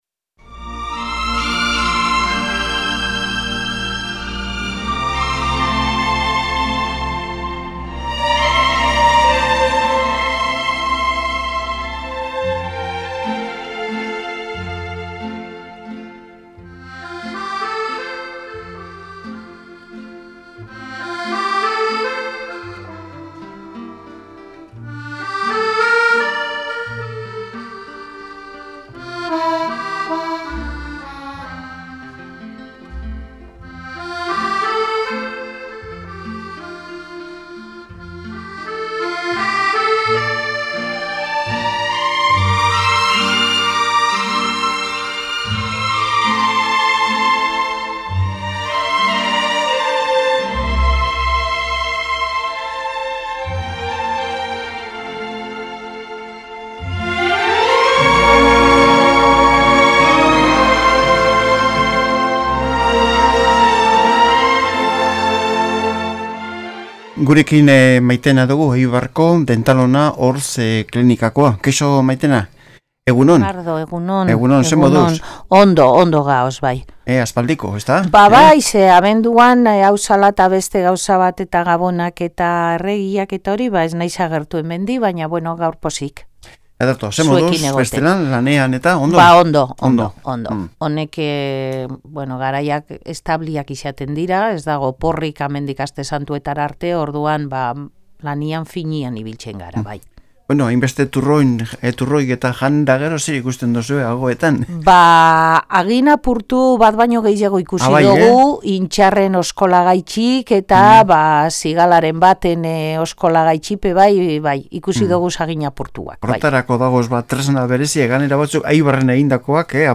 Alkarrizketa Dentalona 26-1-16 Reproducir episodio Pausar episodio Mute/Unmute Episode Rebobinar 10 segundos 1x Fast Forward 30 seconds 00:00 / 33:35 Suscribir Compartir Feed RSS Compartir Enlace Incrustar